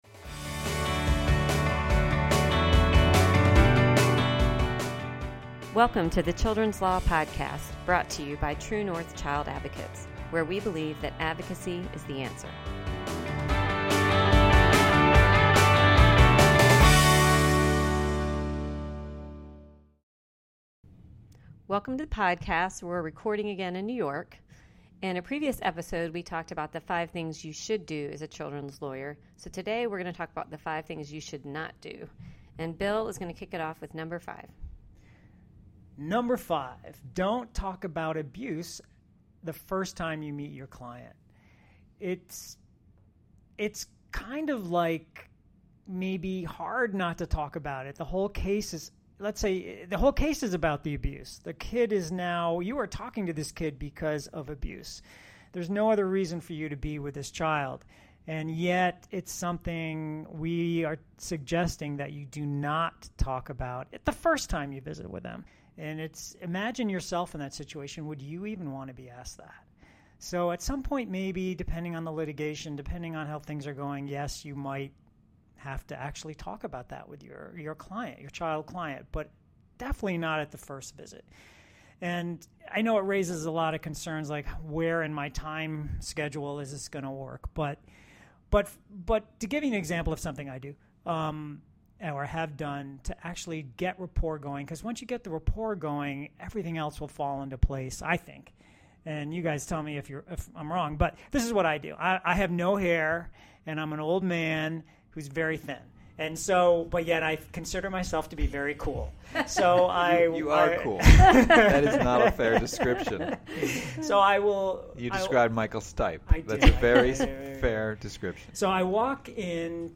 In this episode, the hosts discuss five common mistakes that are made by children's lawyers. This is primarily a discussion about the relationship between the lawyer and the child, and the ways we inadvertently undermine our efforts.